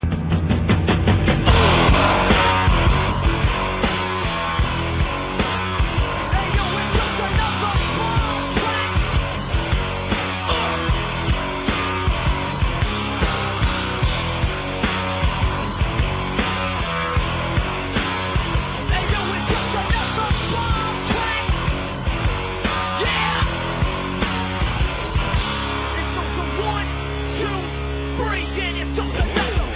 Rock Intros: